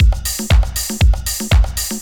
Razz Beat 3_119.wav